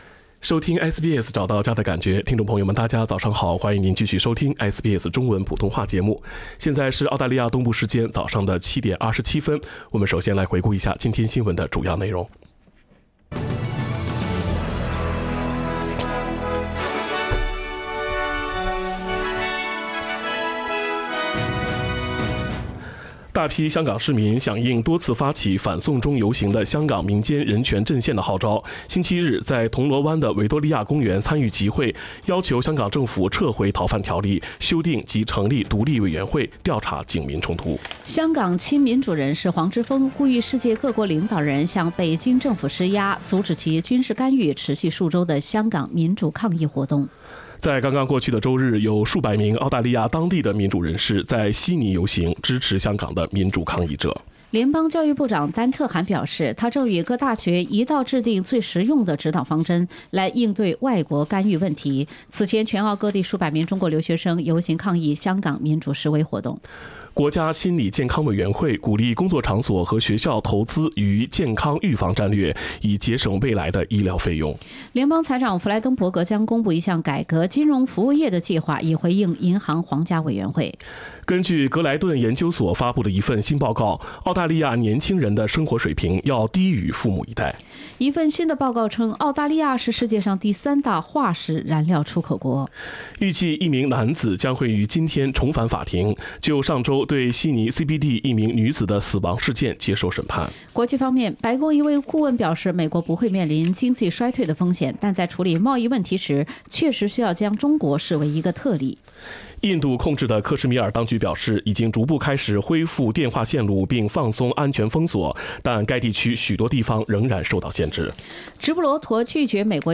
SBS早新闻(8月19日）